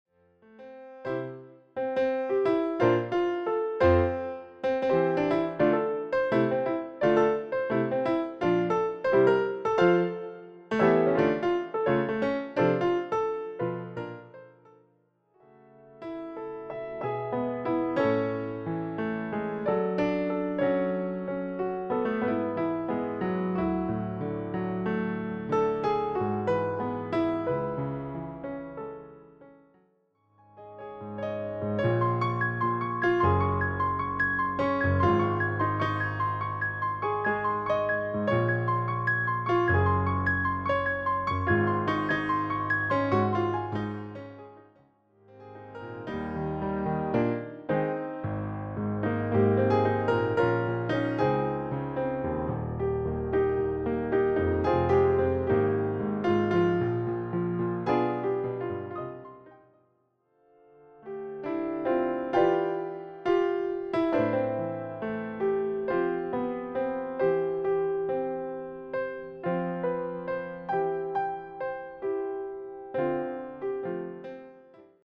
solo piano takes on Broadway material